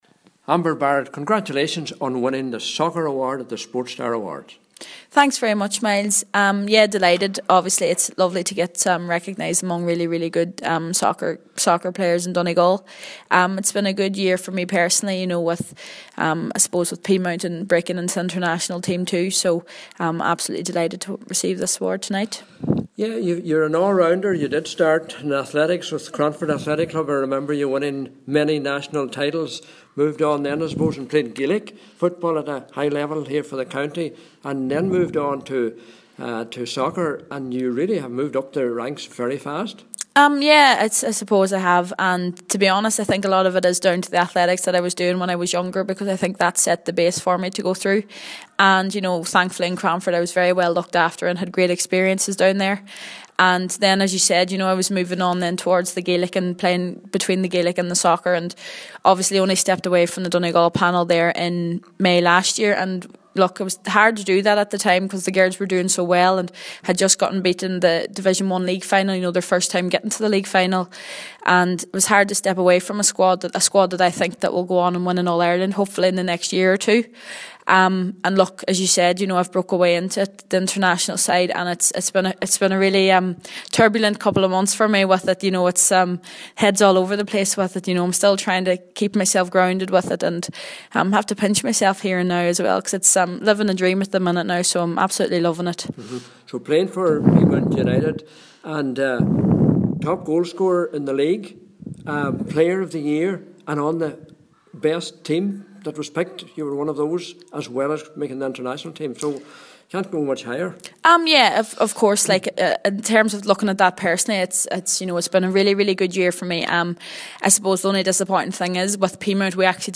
Amber Barrett received the Soccer Award at the Donegal Sports Star Awards in the Mount Errigal Hotel in Letterkenny tonight.